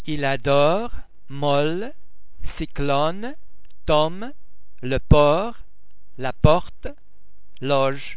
The sound's written representations are: o ô au
o_il-adore.mp3